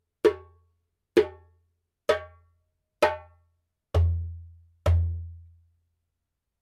Djembe shell made in Mali at KANGABA workshop
これがまたスカっと鳴り鳴りです。
ゲニ材らしいくっきりとした音ですが、固すぎず、心地よい音色です。